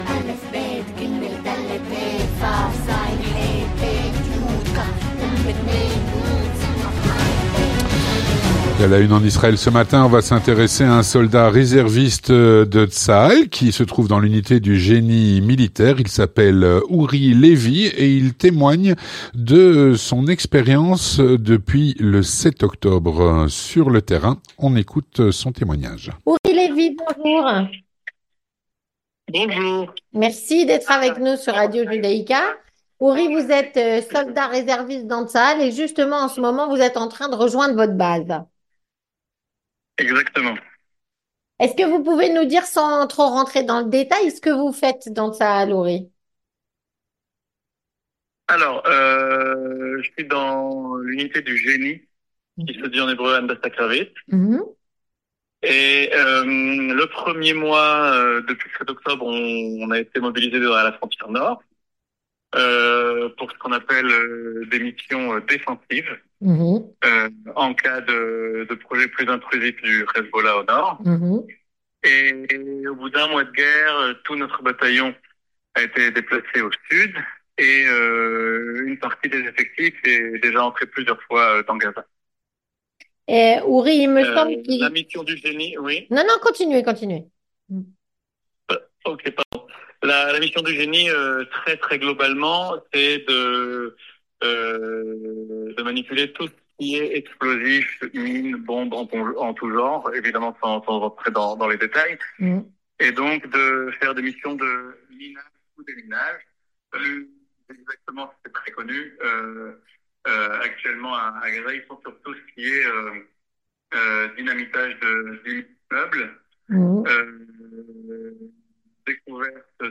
Un soldat de l’unité du génie militaire témoigne de son expérience depuis le 7/10 sur le terrain.